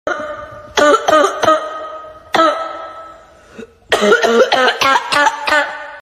Notif WhatsApp Orang Batuk viral TikTok
Kategori: Nada dering
Ini adalah nada dering WA yang lagi viral di TikTok Indonesia sekarang.
notif-whatsapp-orang-batuk-viral-tiktok-id-www_tiengdong_com.mp3